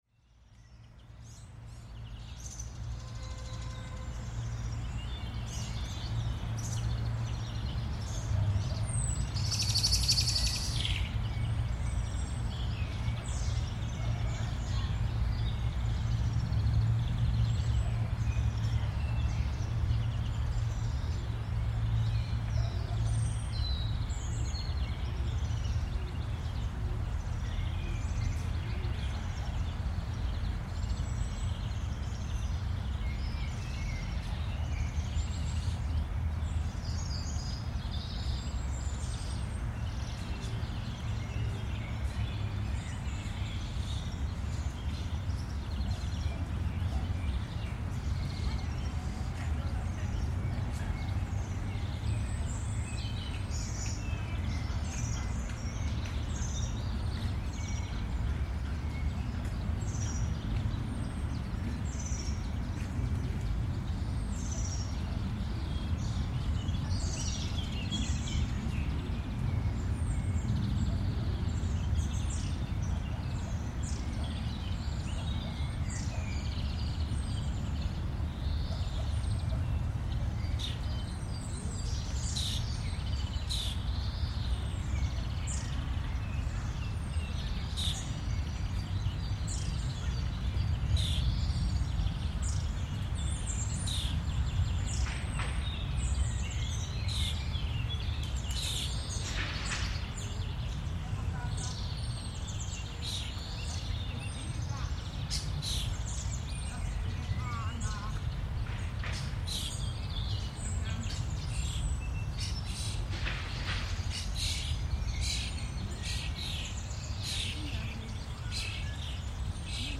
The recording is from April 16, 2025 at 16:27 and was made at the Parco della Pescaia, a small park located in the south-west area of the city of Perugia, Umbria, Italy.
This park bears witness to an ancient time when the soundscape was profoundly different, with a high acoustic definition. Today the park is completely surrounded by roads, buildings and concrete. What you hear is the fragile balance between two acoustic ecosystems.